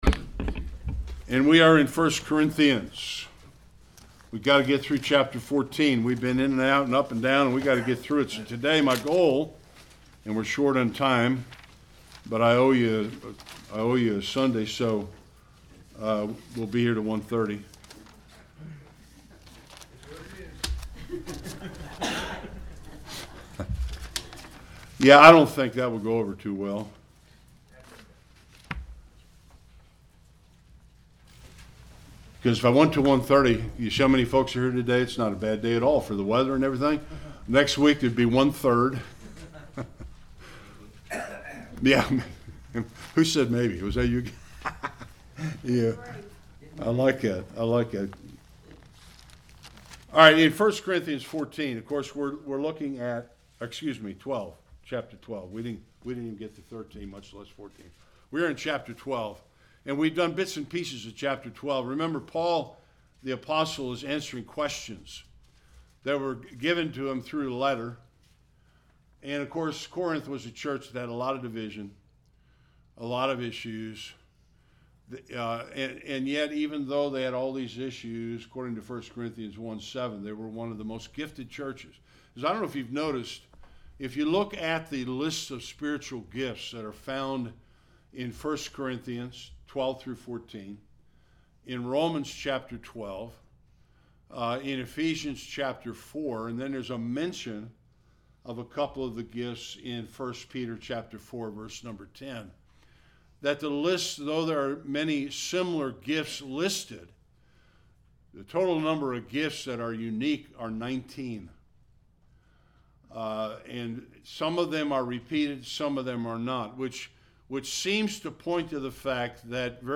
1-31 Service Type: Sunday Worship The importance of harmony and unity in the exercise of Spiritual gifts.